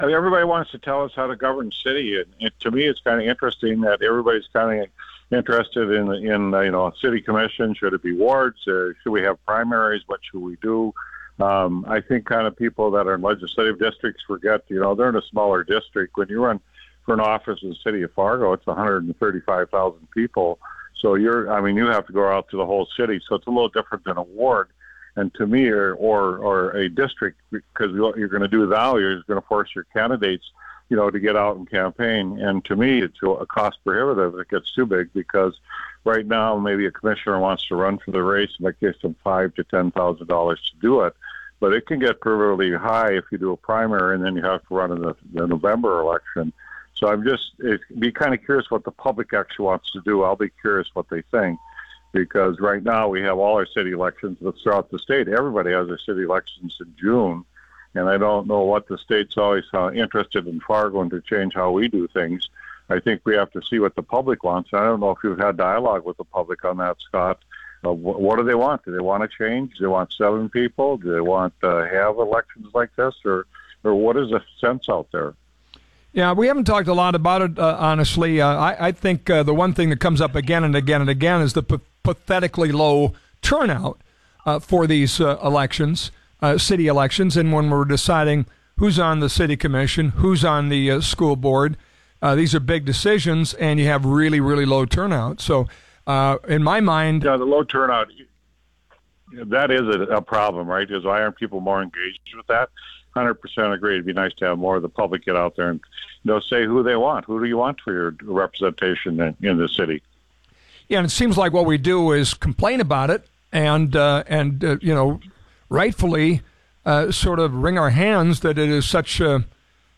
Listen:  Fargo Mayor Tim Mahoney on The Flag’s What’s On Your Mind?